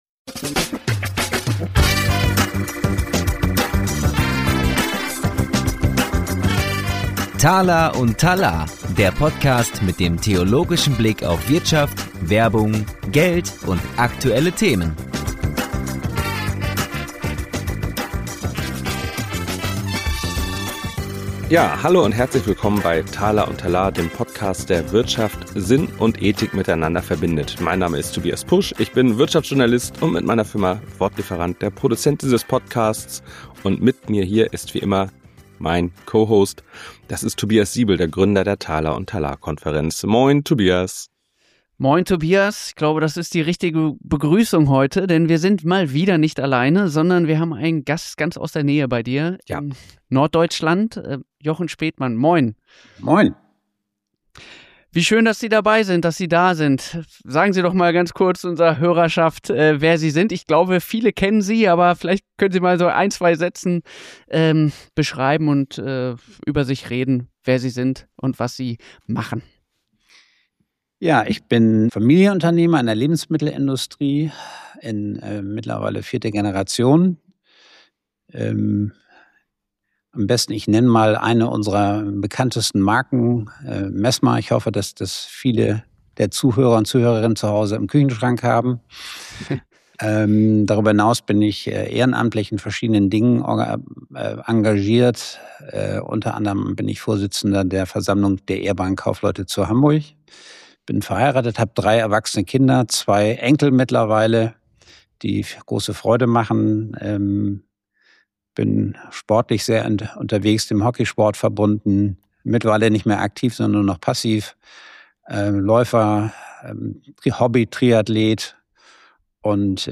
Ein Gespräch über Tee, Tradition und die Härte, die es braucht, damit Werte lebendig bleiben.